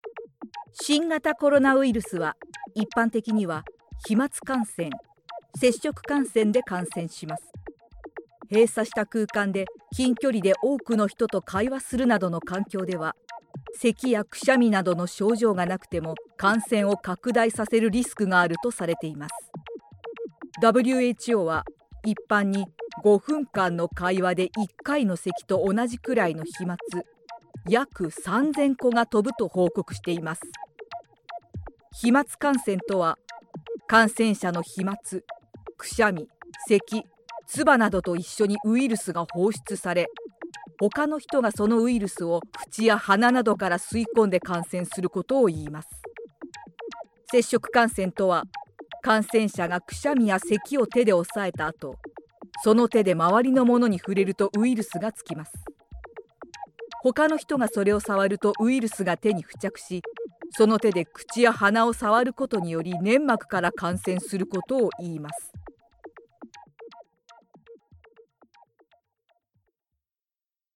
If you need bright clear, charming, healing, entertaining, warm, captivating, sophisticated, trustworthy, calm, magical Japanese voice
versatile, authentic, yet conversational, seductive, and charming voice.
Sprechprobe: Industrie (Muttersprache):